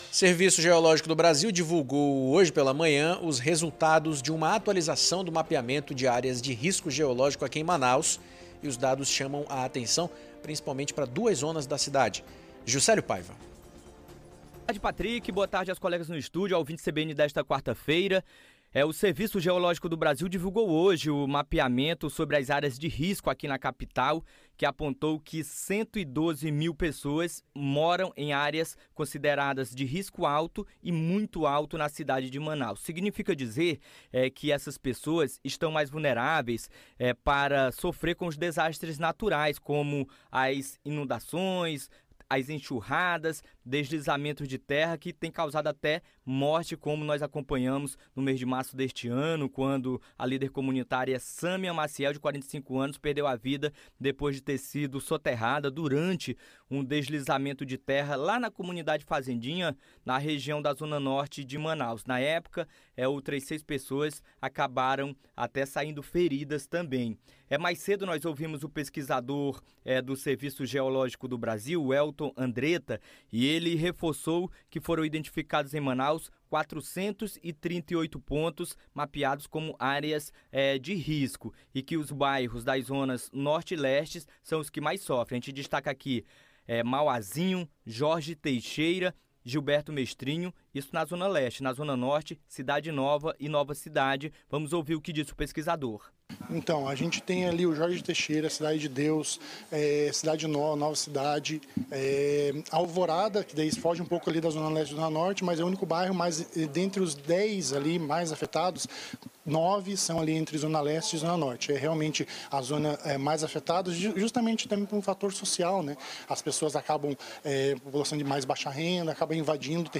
COLETIVA-REA-DE-RISCO.mp3